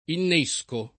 innescare v. («fornire d’esca, d’innesco»); innesco [